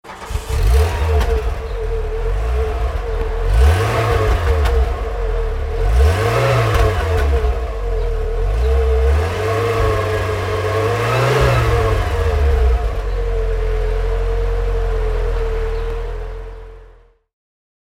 VW Golf Country (1991) - Starten und Leerlauf